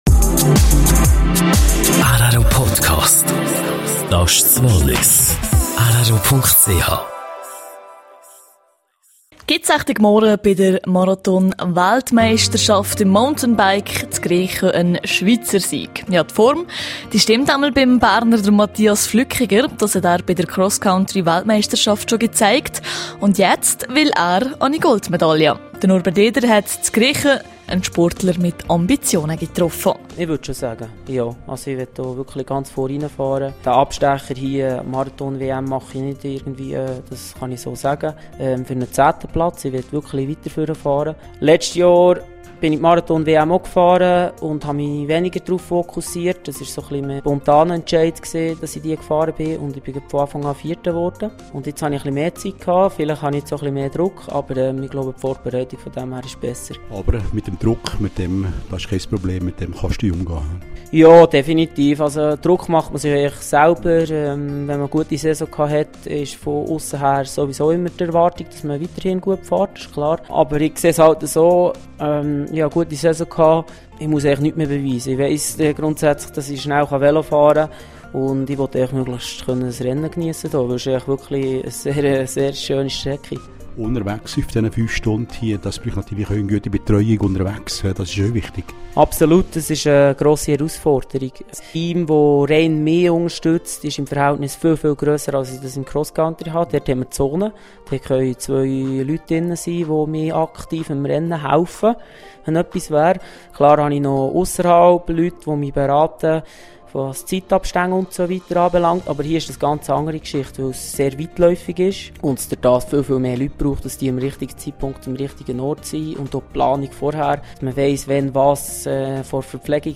Mountain Bike WM Grächen: Interview mit Topfavorit Mathias Flückiger - Teil 1.